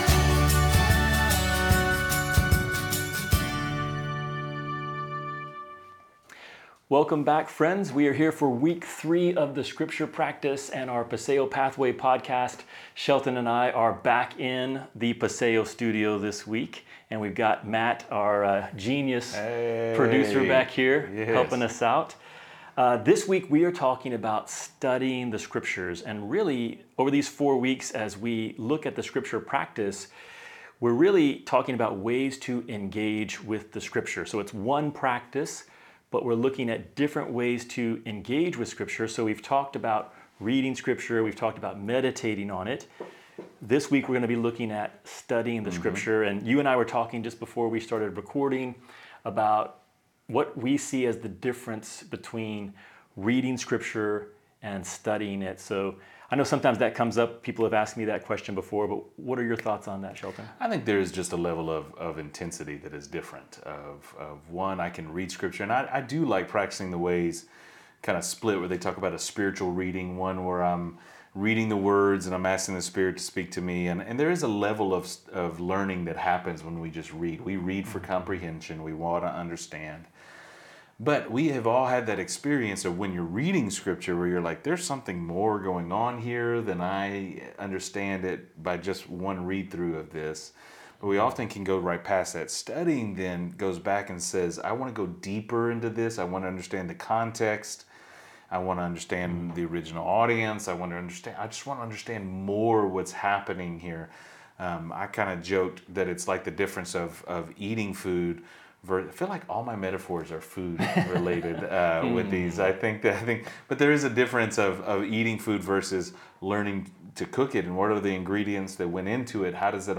We hope this conversation encourages you in your faith!